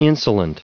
Prononciation du mot insolent en anglais (fichier audio)
Prononciation du mot : insolent